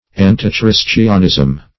Search Result for " antichristianism" : The Collaborative International Dictionary of English v.0.48: Antichristianism \An`ti*chris"tian*ism\, Antichristianity \An`ti*chris*tian"i*ty\, n. Opposition or contrariety to the Christian religion.